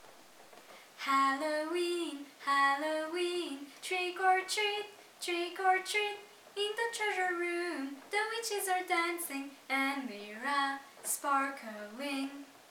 ♫ (Tune of Brother John)